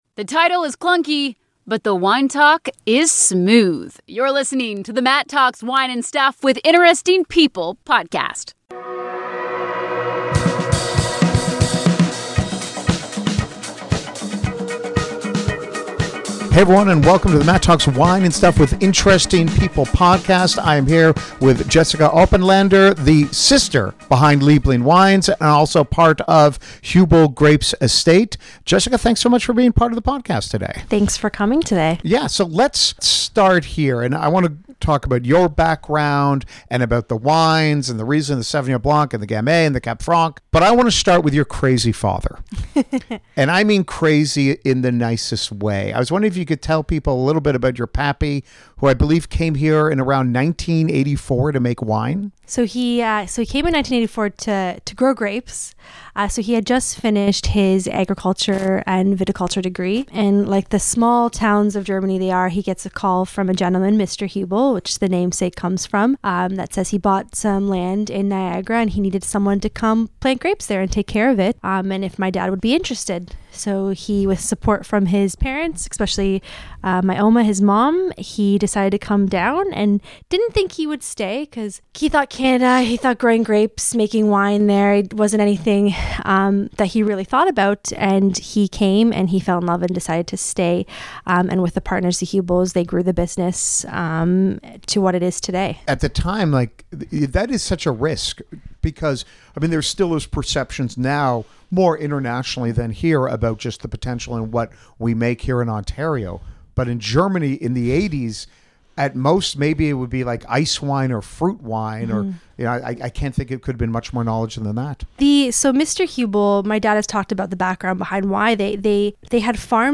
This is a fantastic story about wine but really more about family. A compelling/fun interview that I guarantee you will enjoy.